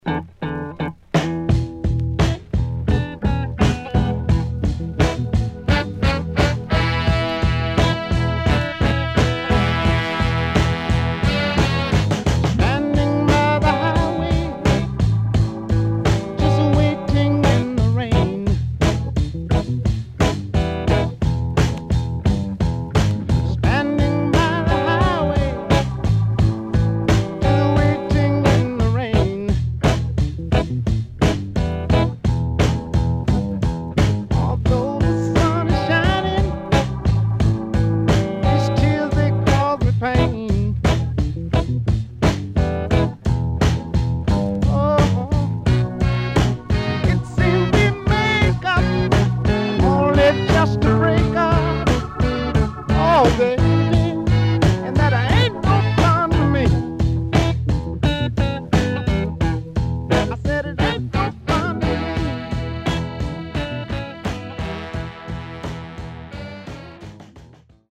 HOME > Back Order [SOUL / OTHERS]
SIDE A:少しノイズ入りますが良好です。